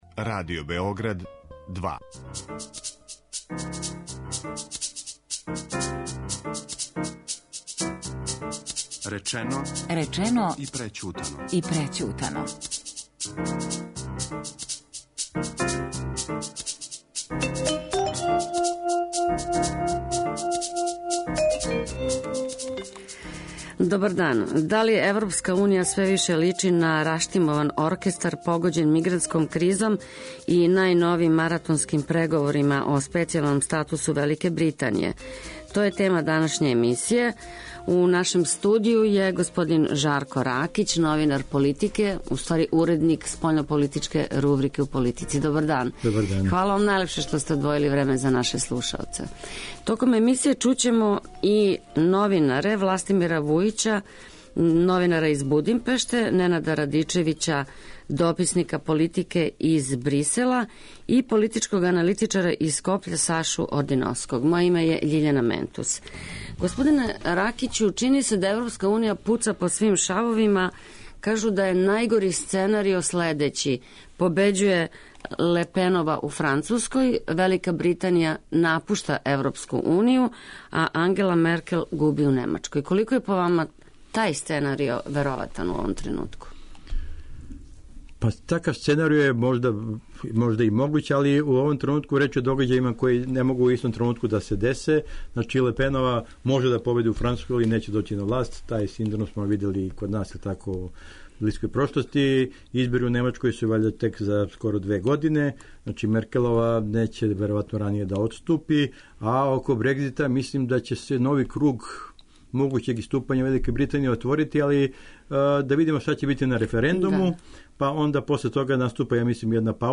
У данашњој дебати